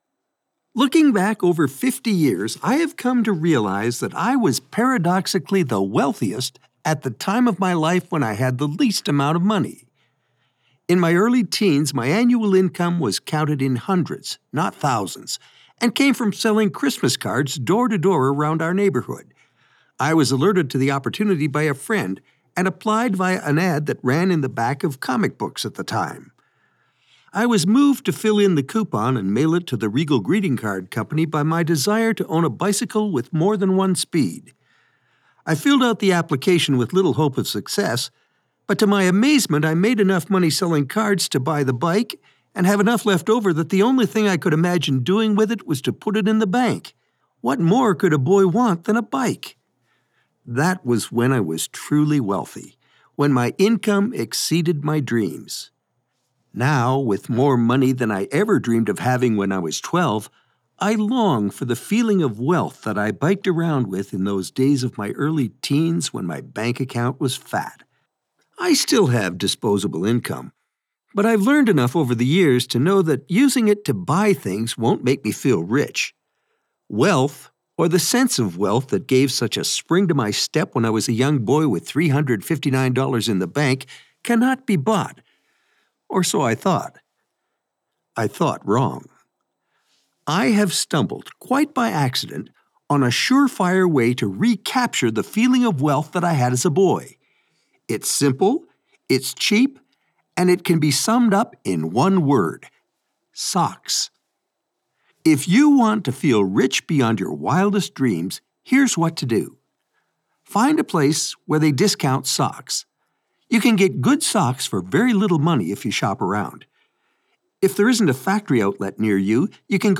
A signature voice - mature, warm, and engaging
SOCKS - audiobook vignette
Middle Aged